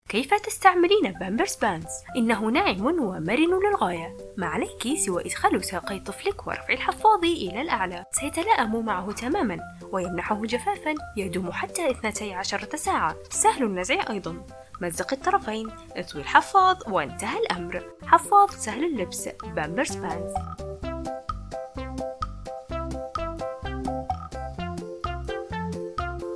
品牌广告【温婉迷人】